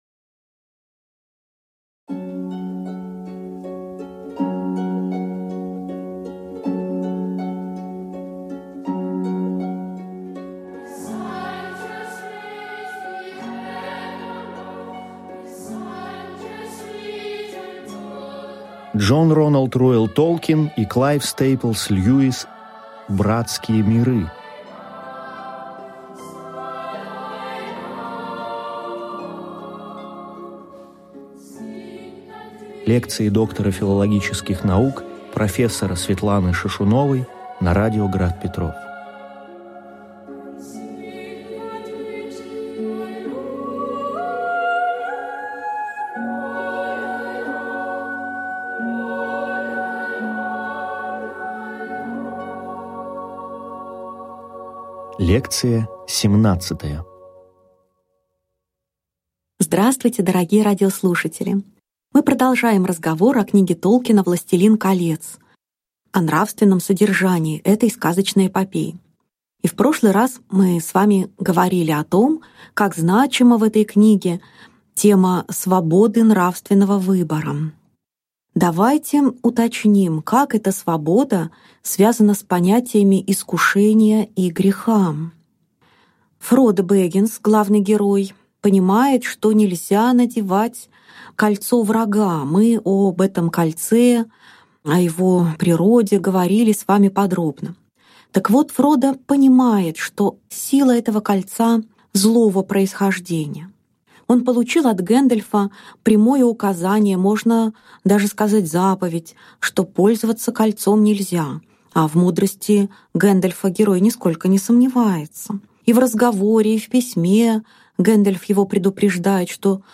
Аудиокнига Лекция 17. Дж.Р.Р.Толкин. «Властелин Колец»: темы духовной брани и милосердия | Библиотека аудиокниг